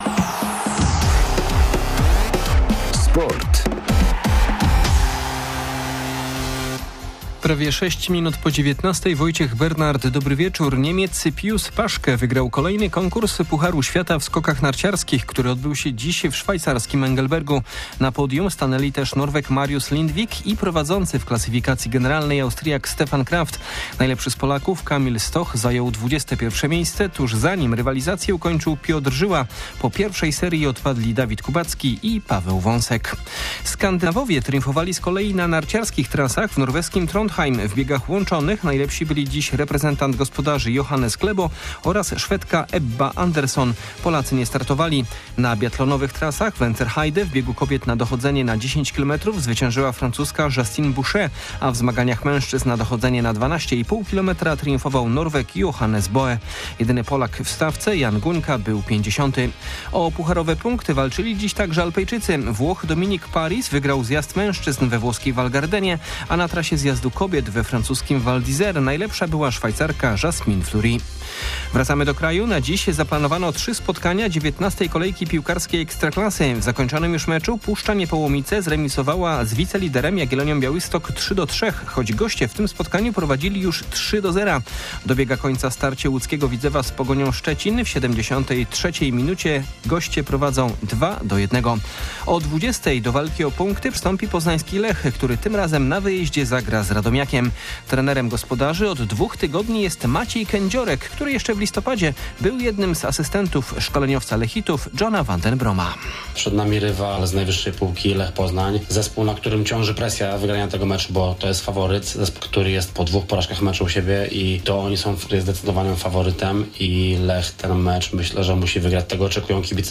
16.12.2023 SERWIS SPORTOWY GODZ. 19:05